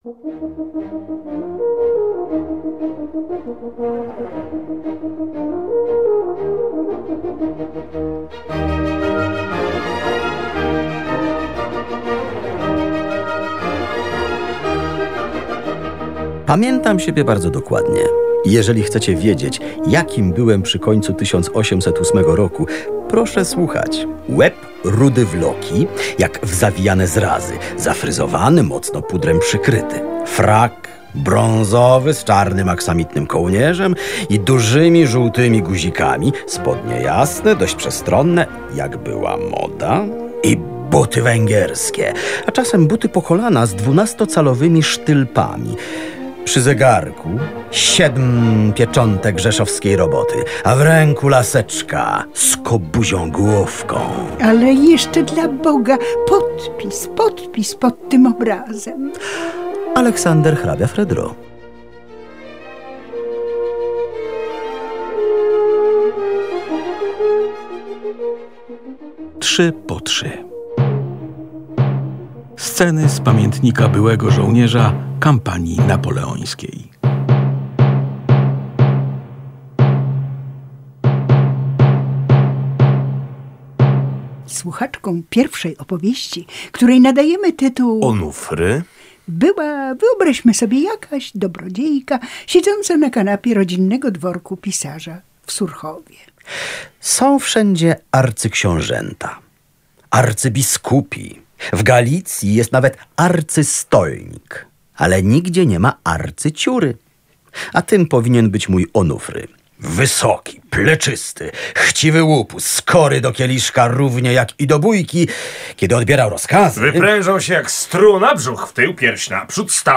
Słuchowisko dzieli się trzy epizody. Pierwszy z nich to „Onufry” – komediowo opisane kłopoty, jakie na głowę Fredry sprowadził w Dreźnie jego służący Onufry.